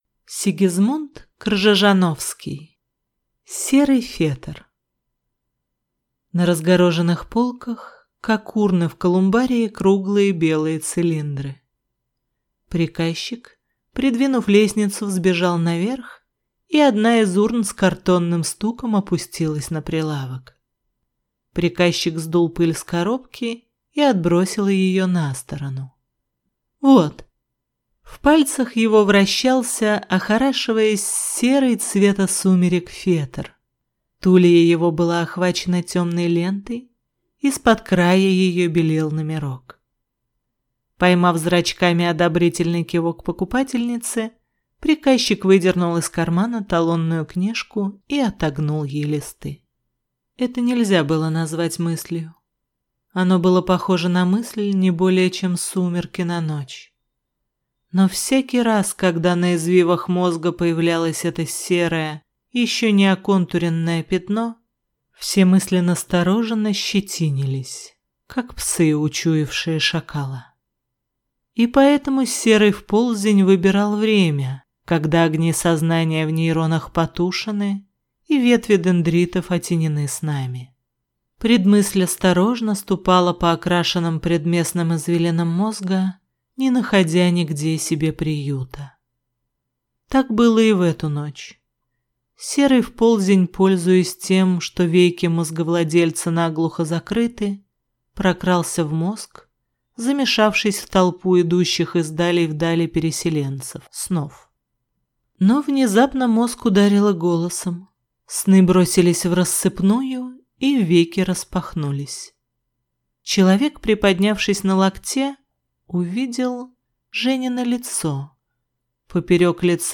Аудиокнига Серый фетр | Библиотека аудиокниг
Прослушать и бесплатно скачать фрагмент аудиокниги